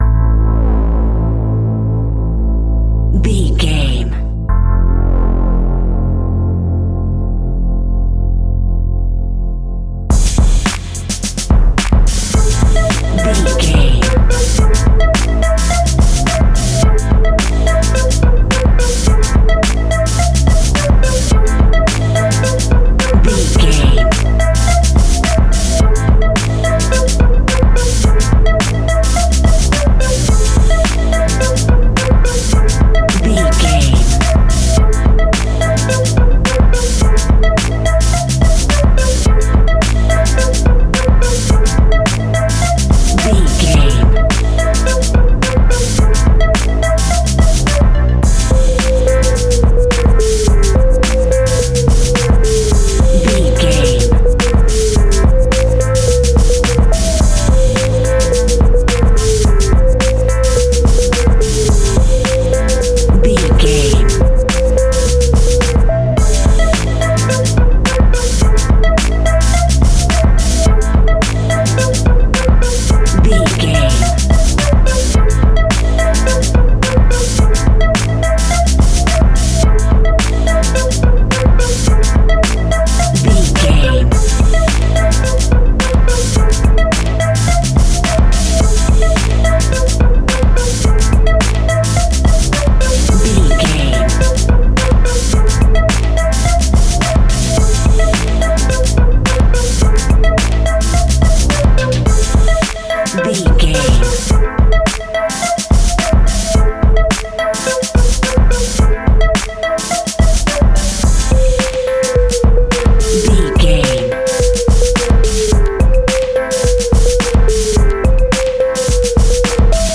Aeolian/Minor
hip hop
hip hop instrumentals
downtempo
synth lead
synth bass
synth drums
turntables